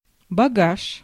Ääntäminen
France: IPA: [ba.ɡaʒ]